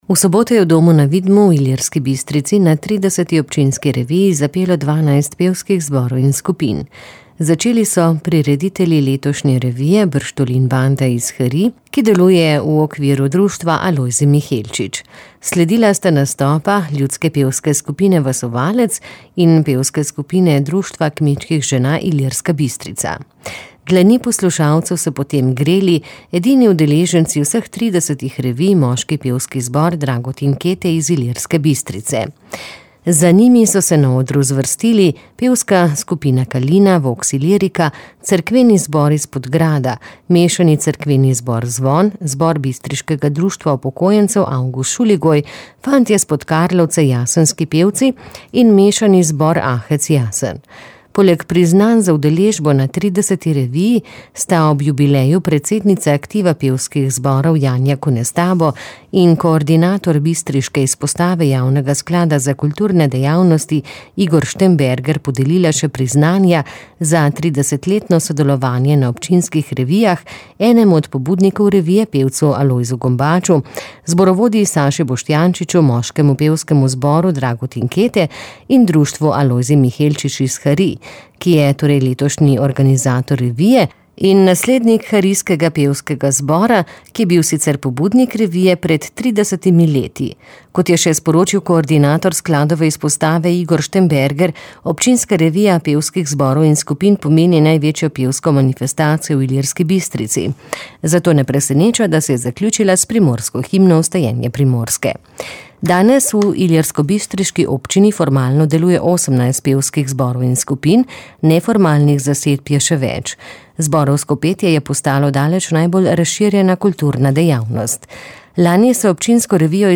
V soboto je v Domu  na Vidmu v Ilirski Bistrici na 30.  občinski reviji zapelo 12 pevskih zborov in skupin.